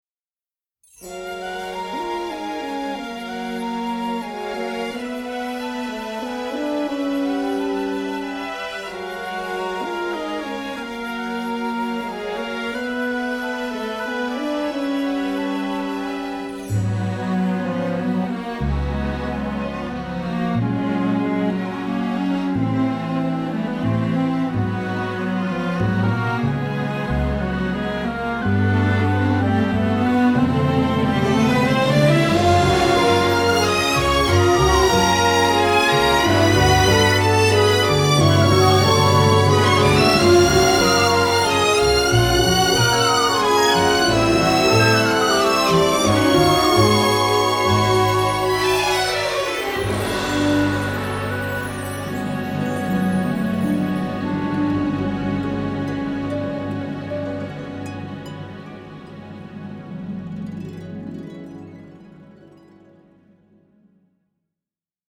A short orchestra piece from my album